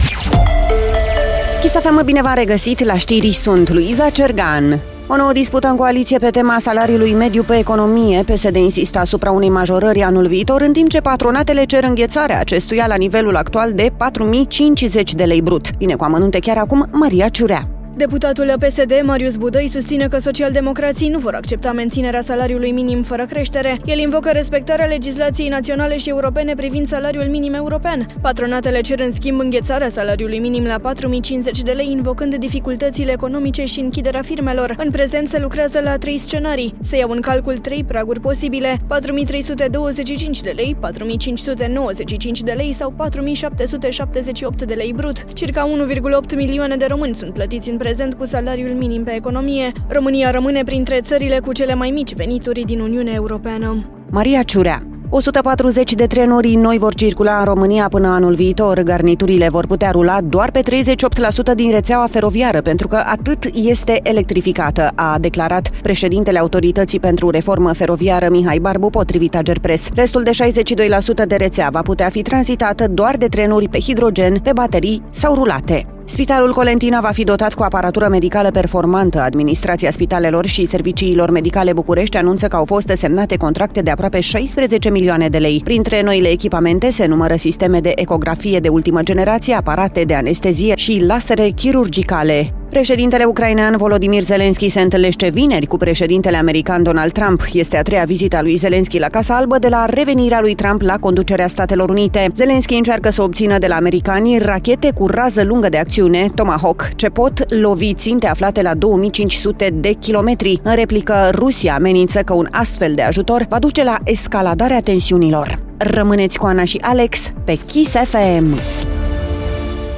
Știrile zilei de la Kiss FM